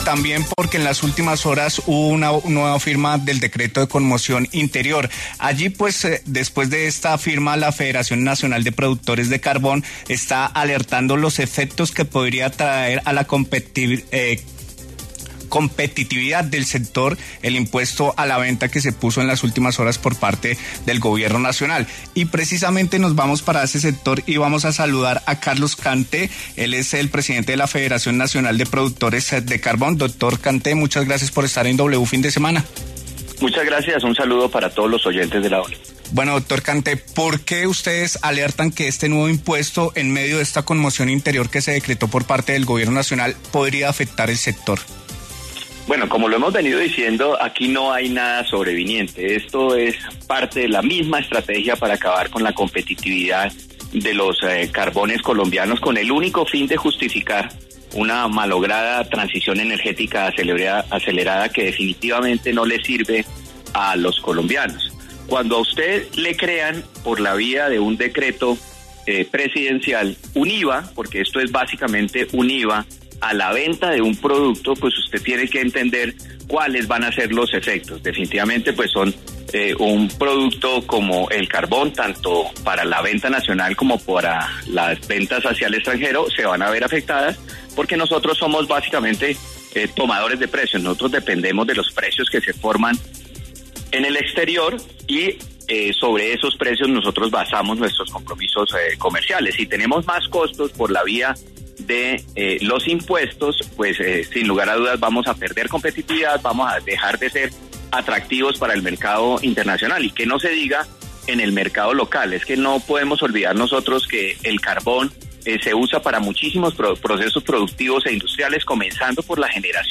De este modo, dijo que uno de los efectos serán las ventas de carbón entre Colombia y el extranjero.